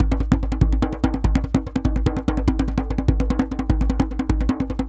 DJEM.GRV05.wav